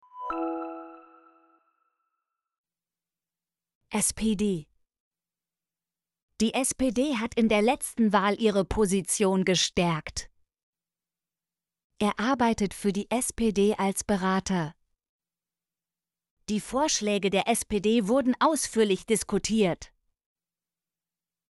spd - Example Sentences & Pronunciation, German Frequency List